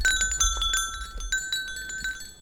Dans cette page nous offrons des sonneries issues d’enregistrements de troupeaux.
Plateau de la Molière (Vercors
Sonnette artisanale